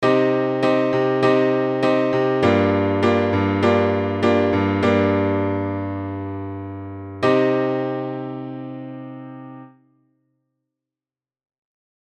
サンプルはKey=Cマイナーで、わかりやすくするために、途中に溜めを作っています。
• ナチュラルマイナー
Im→Vm7→Im　Cm→Gm7→Cm
終わった感じもしますが、メジャーのときのような緊張感や不安定さから解放された感覚はありませんね。